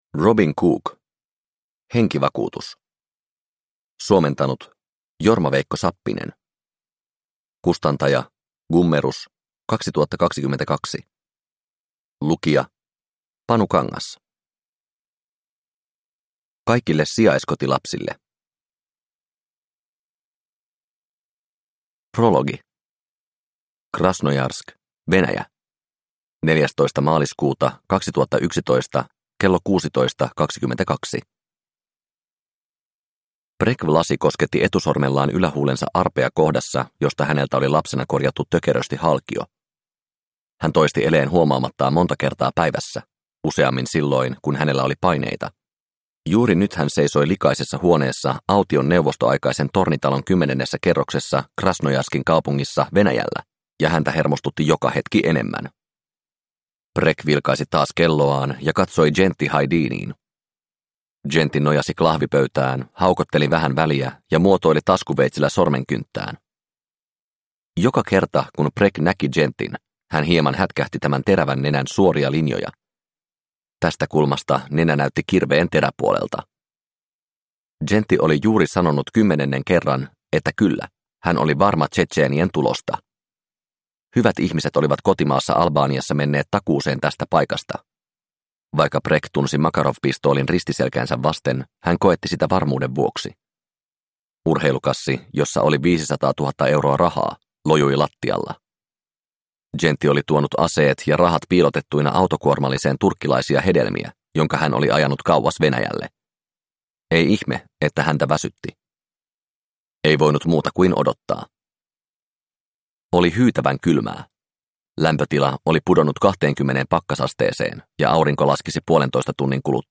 Henkivakuutus – Ljudbok – Laddas ner